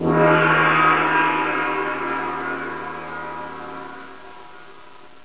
gong.wav